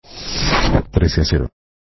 Entrevista con el polifacético Alfredo Casero, que llegó a Montevideo para presentar su último espectáculo "Soloist".